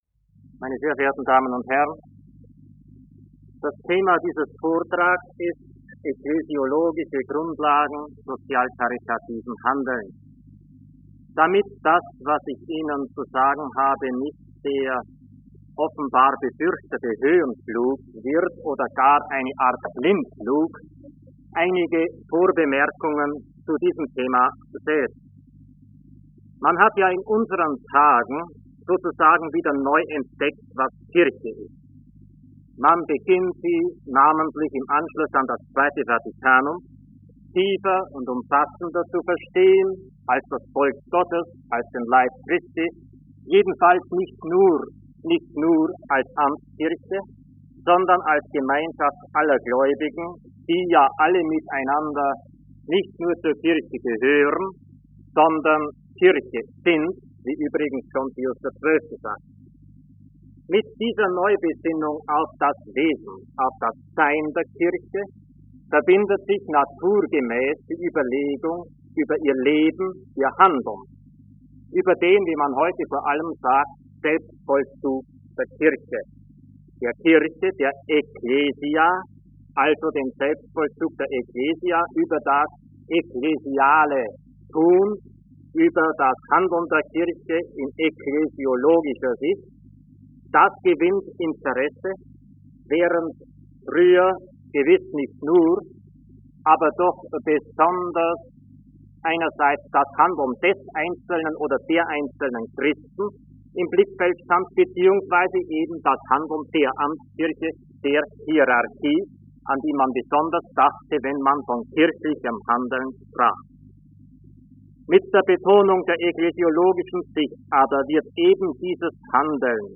Ekklesiologische Grundlagen sozial-caritativen Handelns - Rede des Monats - Religion und Theologie - Religion und Theologie - Kategorien - Videoportal Universität Freiburg